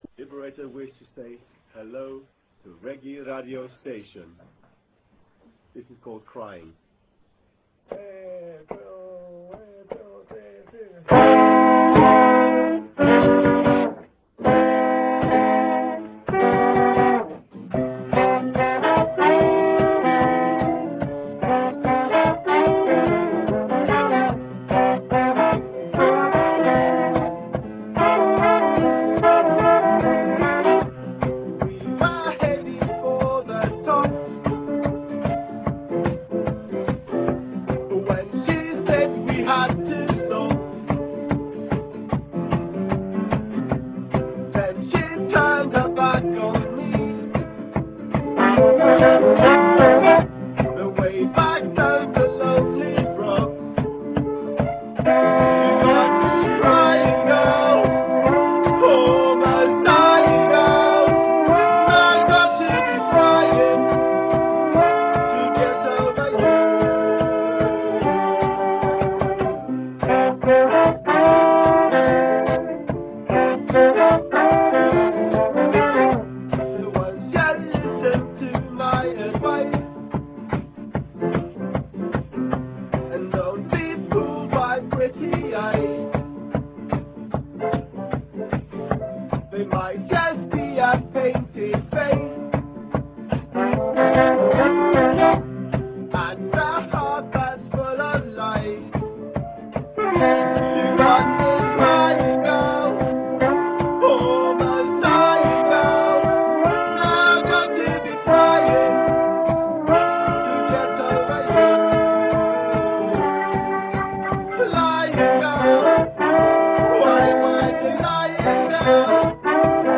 (unplugged) registrata in studio